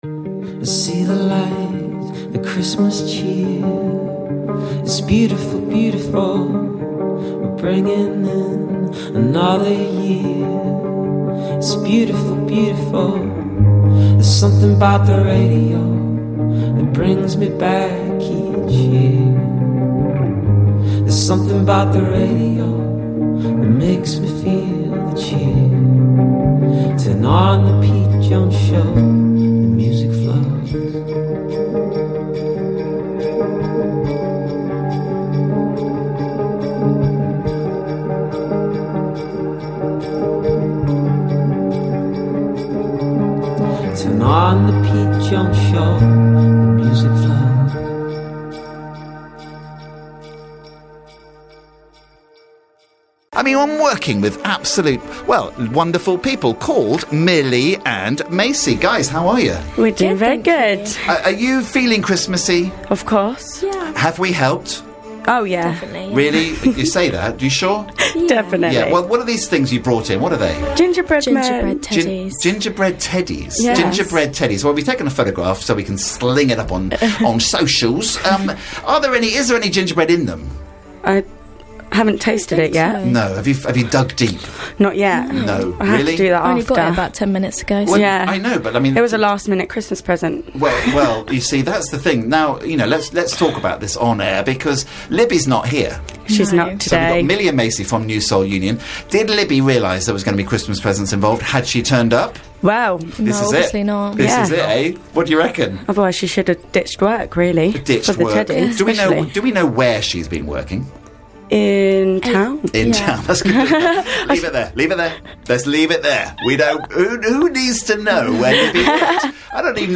(rough studio version)